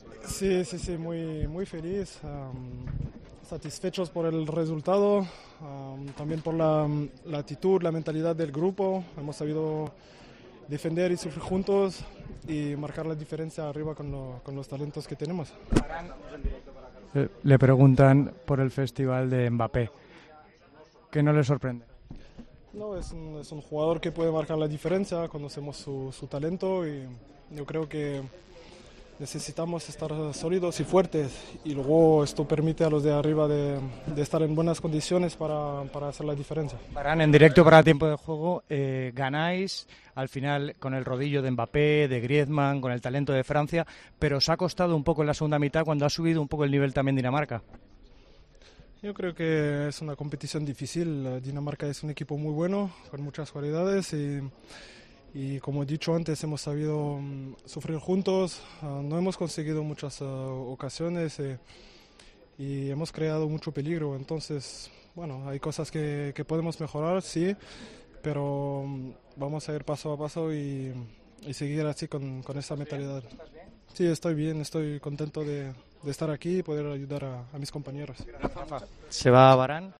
El central de Francia habló